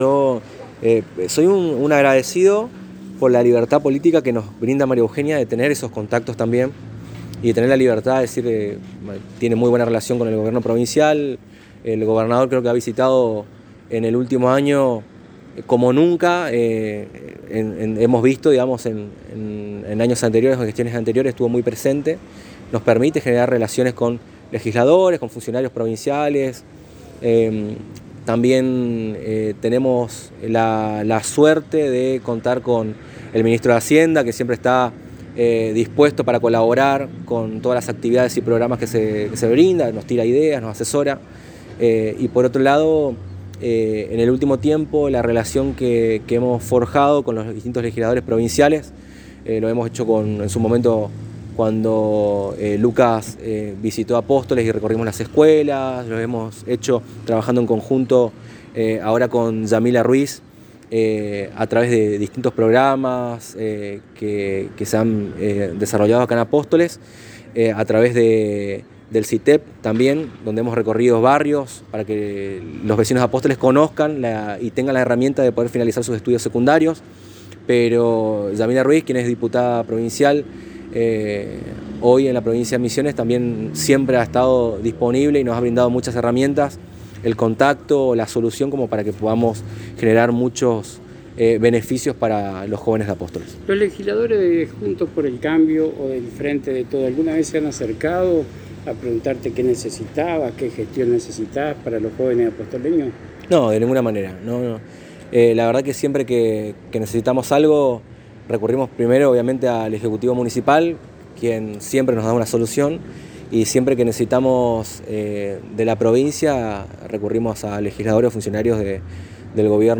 En diálogo exclusivo con la ANG el joven Director de la Juventud de Apóstoles Aldo Muñoz al ser consultado por quien o quienes se siente acompañado en la gestión a favor de los jóvenes resaltó que la Intendente María Eugenia Safrán está siempre atenta a qué herramientas se les puede brindar al sector joven y destacó la actitud generosa de la Intendente de apoyar y promover la buena relación de parte de la Dirección de la Juventud con Legisladores y Funcionarios de la Renovación que son quienes aportan para el enriquecimiento de las herramientas que ya brinda el Municipio.